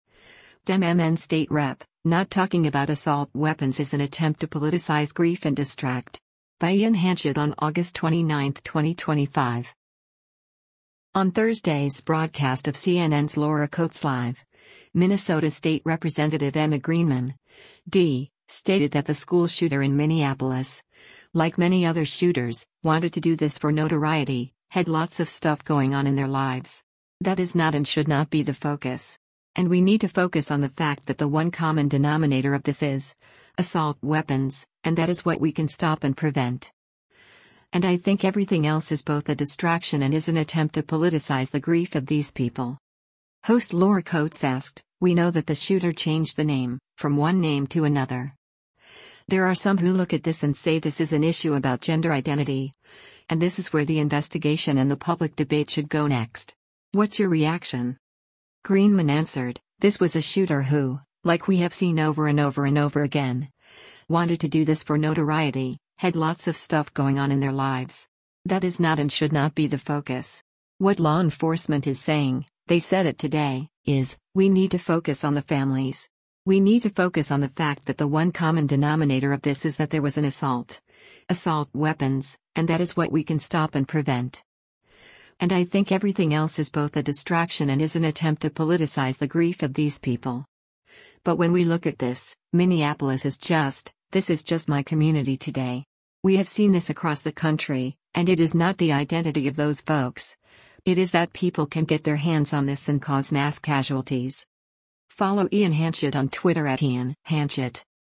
On Thursday’s broadcast of CNN’s “Laura Coates Live,” Minnesota State Rep. Emma Greenman (D) stated that the school shooter in Minneapolis, like many other shooters, “wanted to do this for notoriety, had lots of stuff going on in their lives. That is not and should not be the focus.”